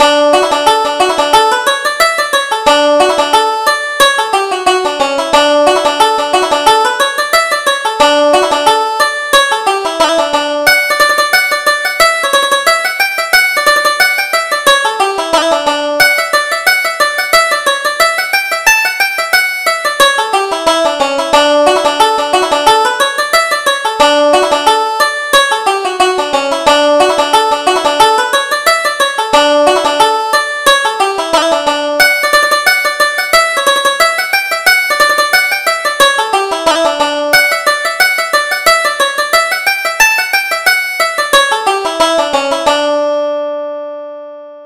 Reel: The Green Jacket